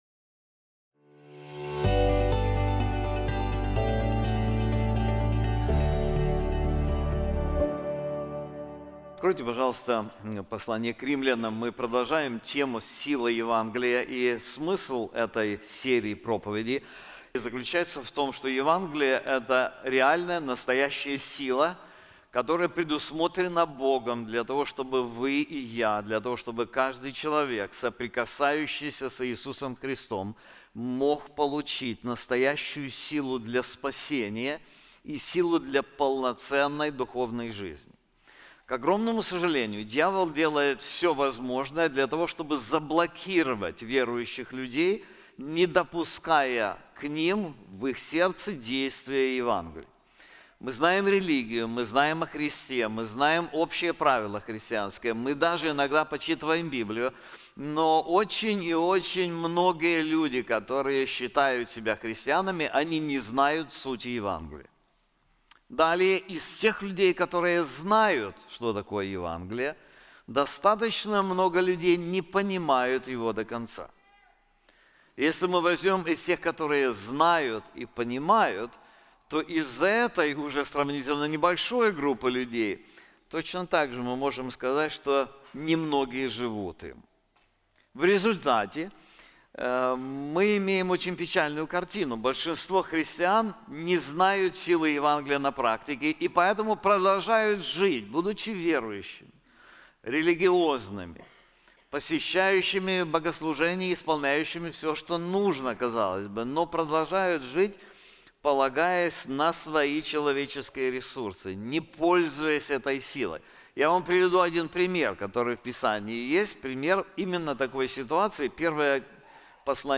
This sermon is also available in English:The Gospel: The Foundation of Confidence in God • Romans 8:26-30